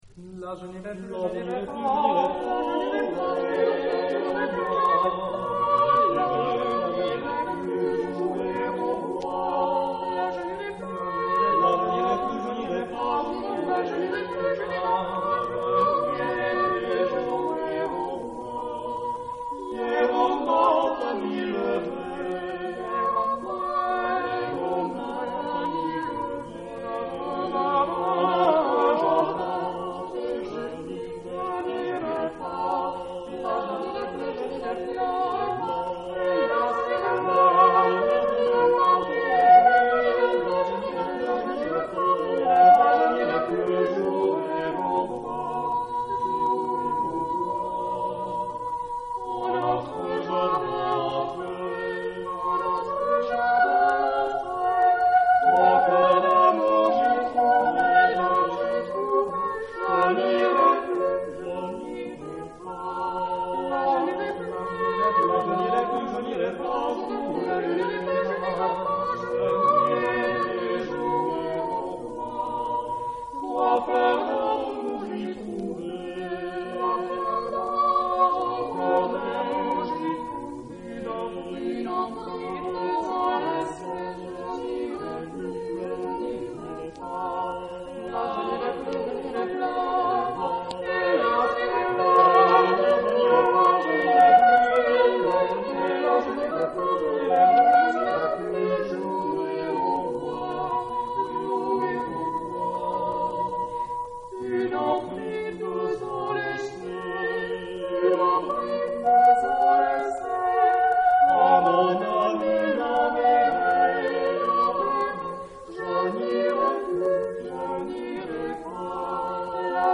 Género/Estilo/Forma: Renacimiento ; Canción ; Profano
Tipo de formación coral: SATB  (4 voces Coro mixto )
Tonalidad : sol mayor